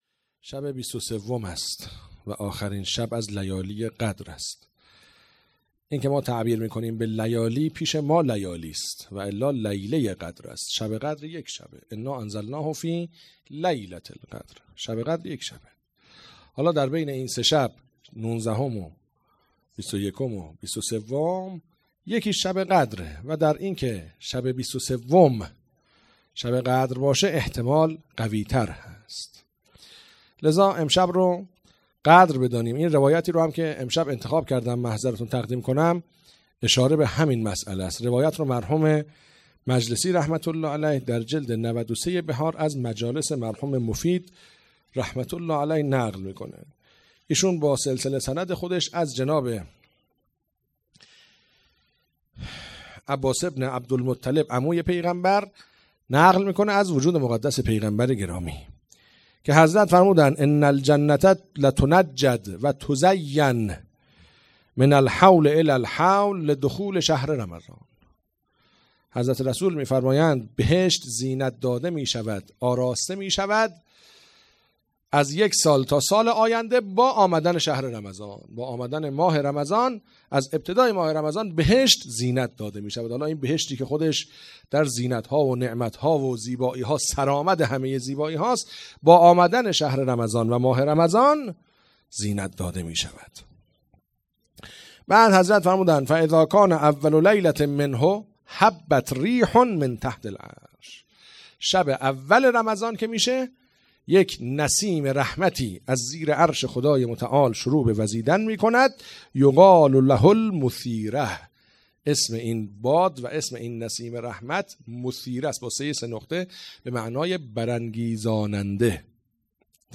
هیئت عقیله بنی هاشم سبزوار